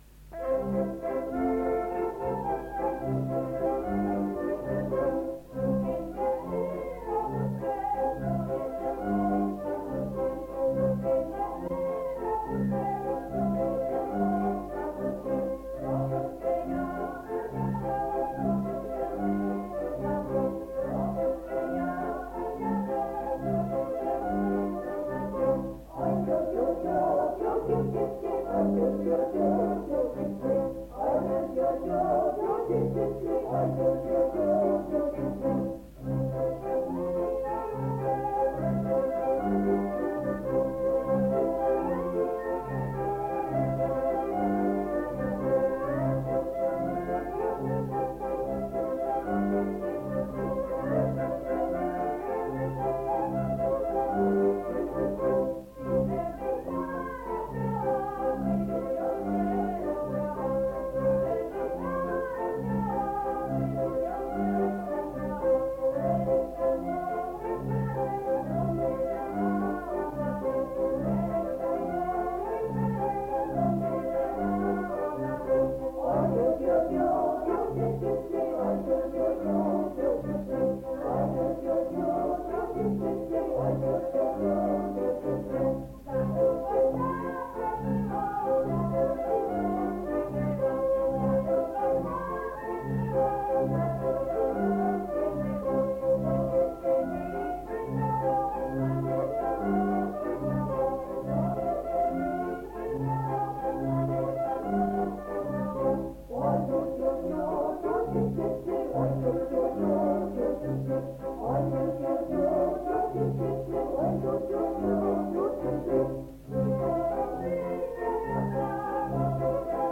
Nagranie archiwalne